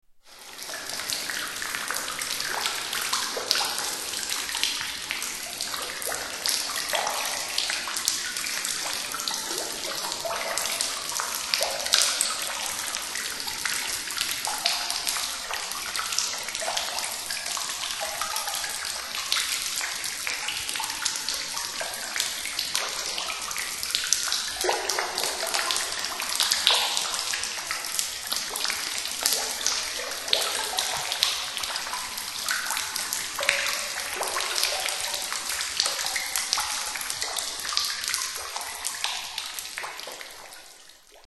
Water dripping in subterranean caverns
Category: Animals/Nature   Right: Personal
Tags: Science and Nature Wildlife sounds Bristish Animals British Wildlife sounds United Kingdom